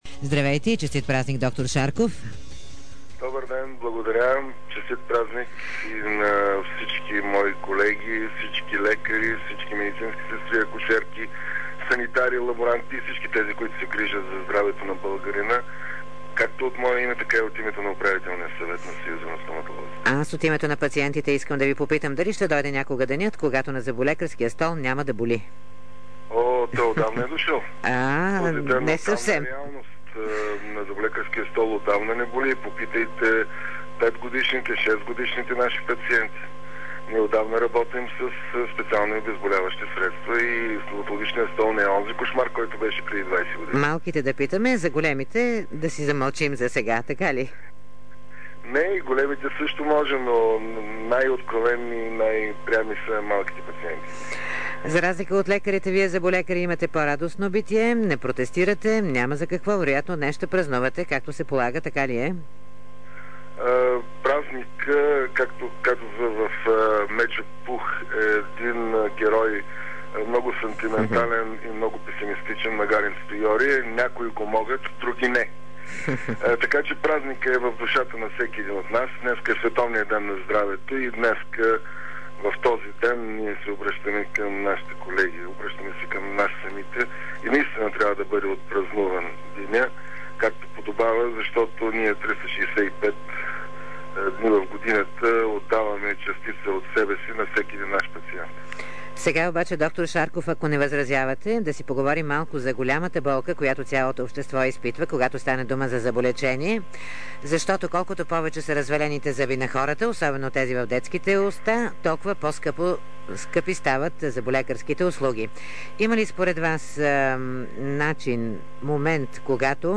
по повод Световния ден на здравето в интервю за предаването „Дарик кафе”.